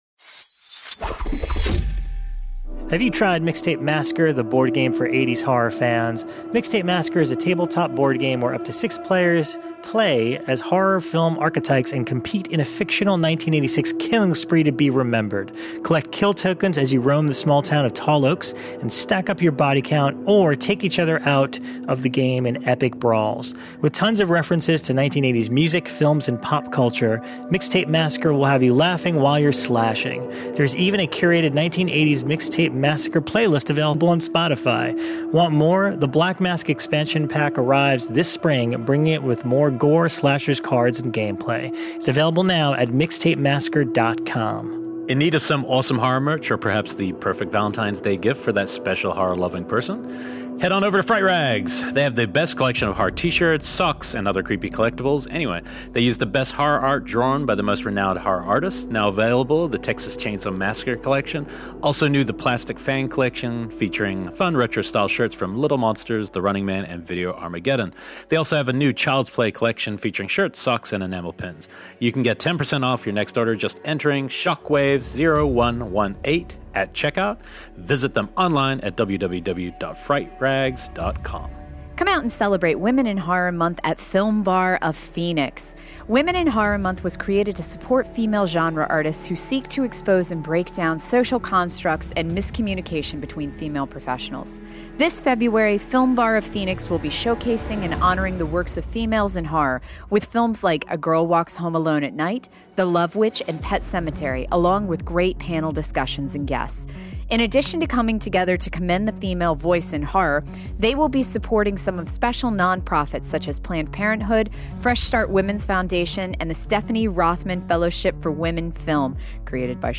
Kick back, relax, and enjoy the conversation!